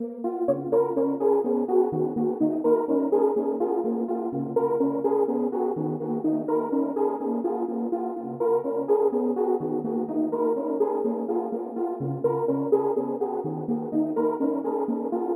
描述：短小的欢快的电子音乐循环
标签： 开心 循环 电子 音乐
声道立体声